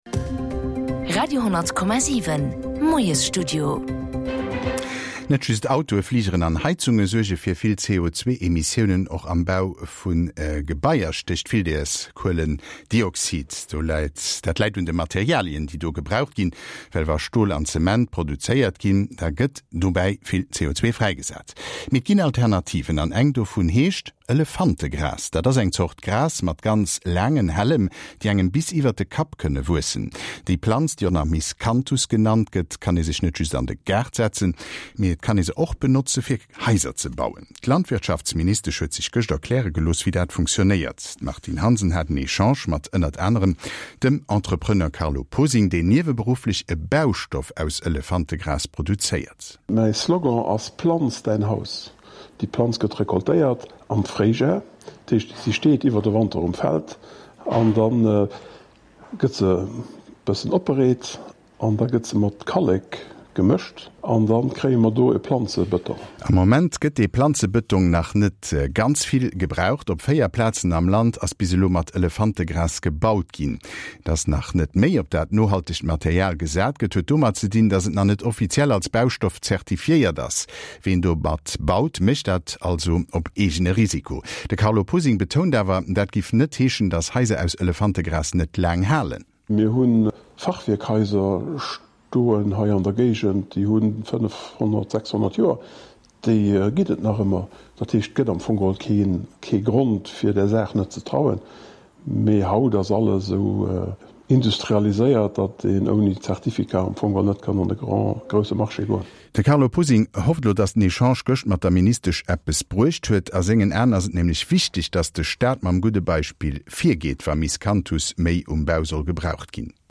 Le miscanthus (ou herbe à éléphant) peut non seulement être placé dans le jardin, mais il peut également être utilisé pour fabriquer du béton végétal. Nous avons parlé avec un producteur.